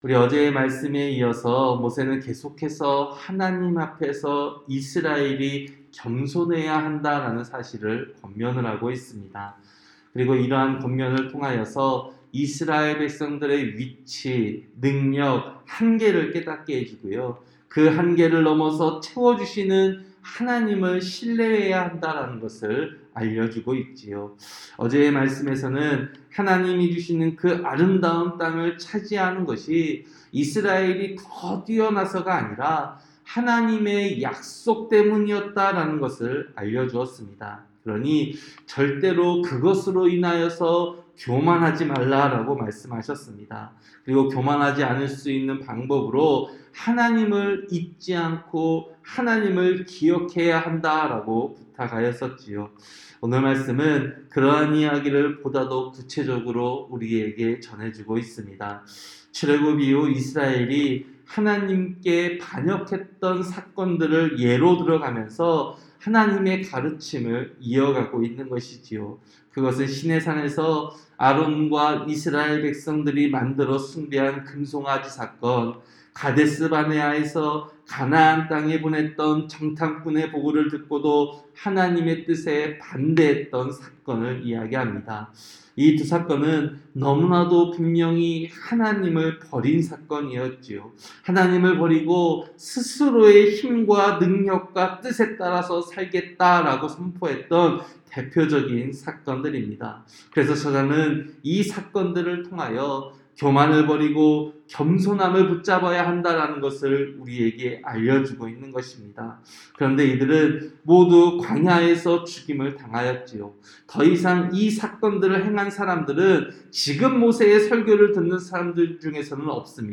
새벽설교-신명기 9장